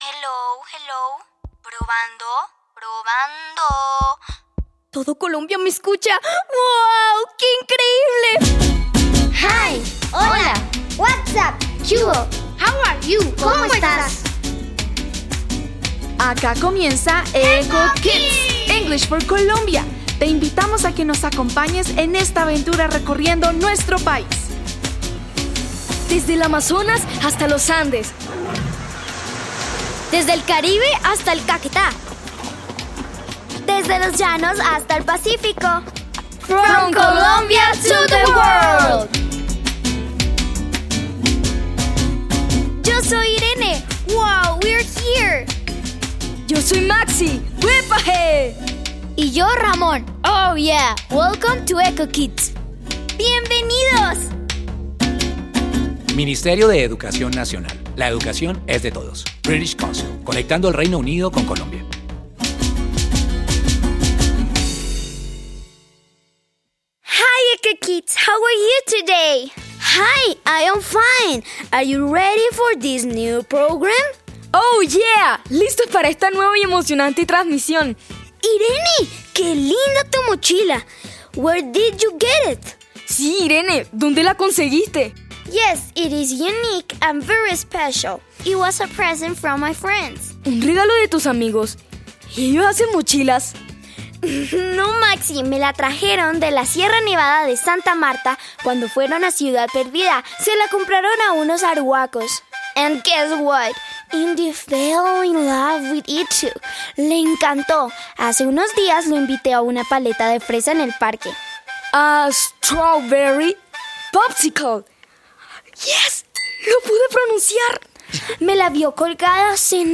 Introducción Este recurso ofrece un episodio radial de Eco Kids sobre la cultura Arhuaca. Presenta diálogos sencillos en inglés y elementos culturales para apoyar el aprendizaje de manera contextualizada.